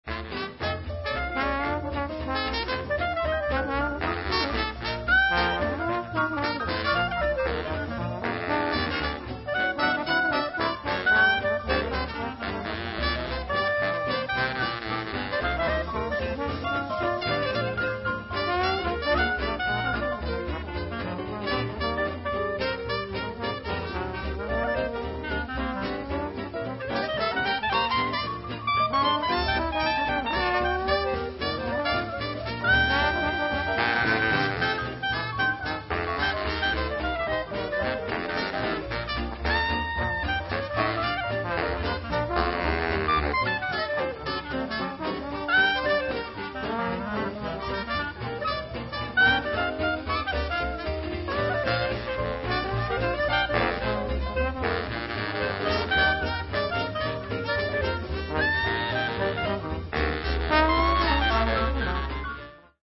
cornetta
trombone
clarinetto
pianoforte
contrabbasso
batteria